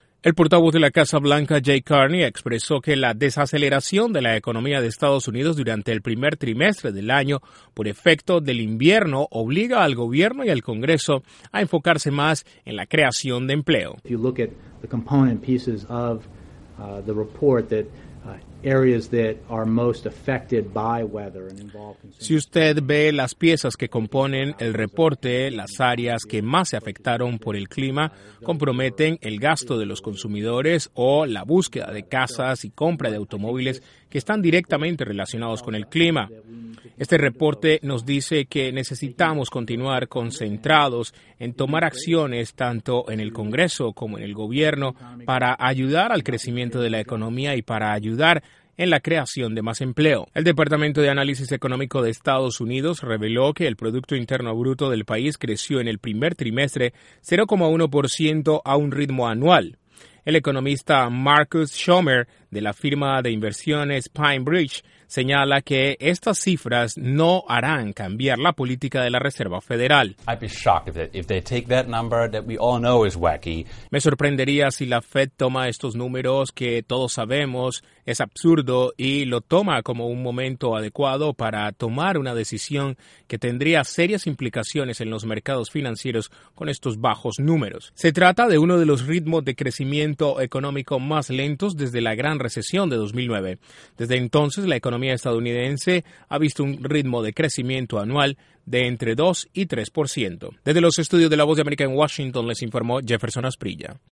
La Casa Blanca dice que caída PIB en el primer trimestre a 0,1% por ciento se debe al invierno y ello obliga a la creación de más puestos de trabajo. Desde la Voz de América en Washington informa